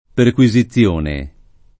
perquisizione [ perk U i @ i ZZL1 ne ] s. f.